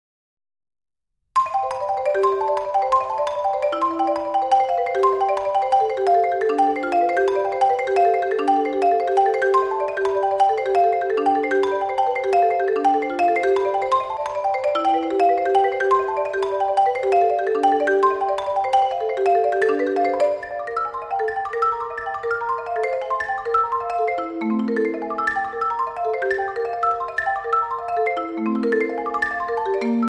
for solo marimba